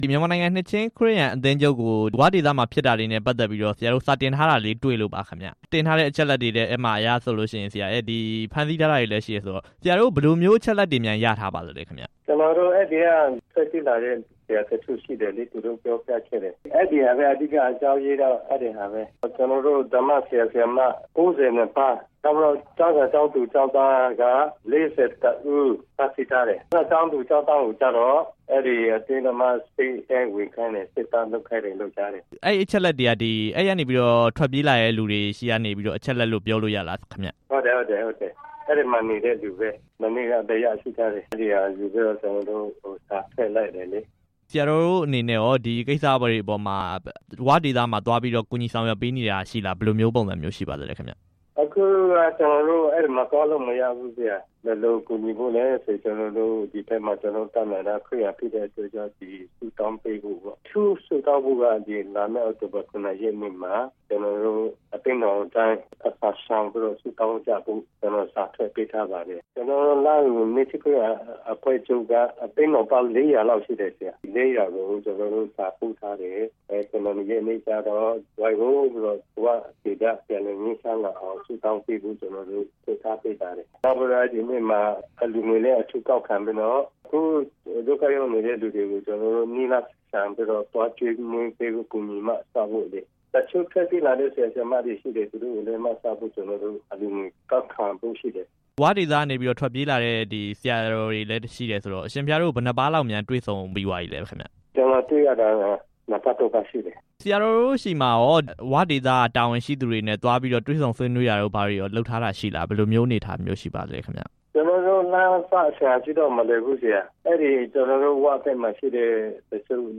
ဝ,အထူးဒေသ ဖမ်းဆီးမှုကိစ္စ လားဟူခရစ်ယာန်အဖွဲ့ချုပ်နဲ့ မေးမြန်းချက်